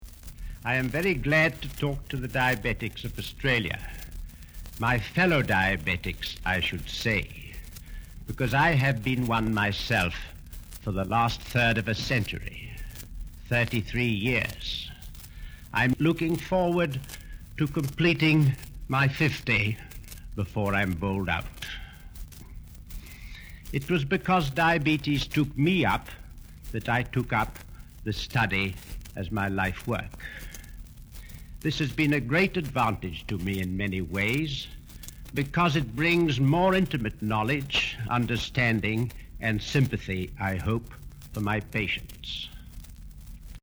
This is a recording of a speech made in 1953.